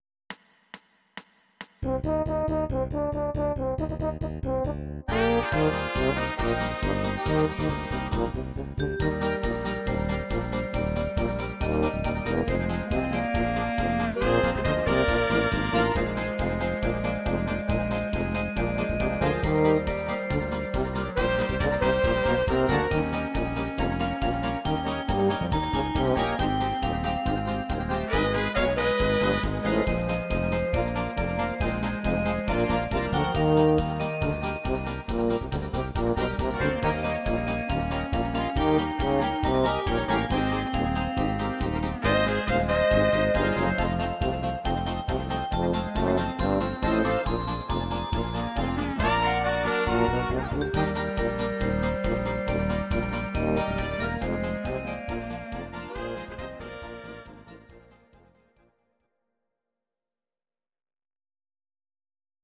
These are MP3 versions of our MIDI file catalogue.
Your-Mix: Volkstï¿½mlich (1262)
Oberkrainer Sound